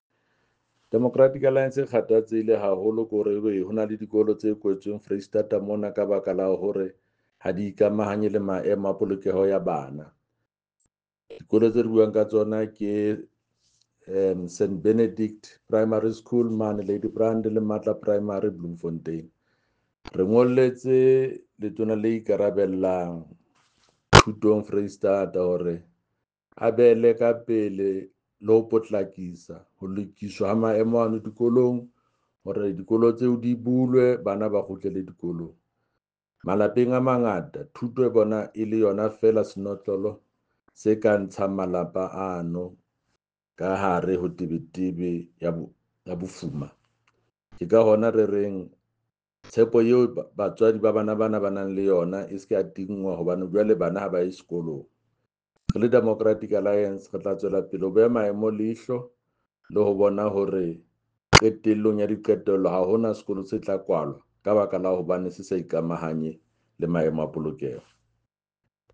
Sesotho soundbites by Jafta Mokoena MPL and